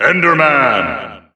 The announcer saying Enderman's name in English and Japanese releases of Super Smash Bros. Ultimate.
Enderman_English_Announcer_SSBU.wav